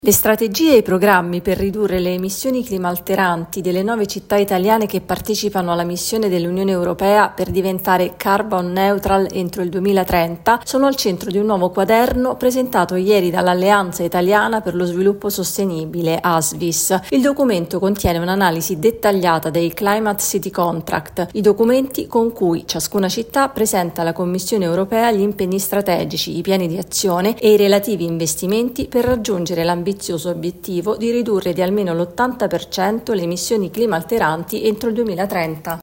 Sono 9 le città italiane che corrono verso l’obiettivo secondo Asvis – Alleanza italiana per lo sviluppo sostenibile. Il servizio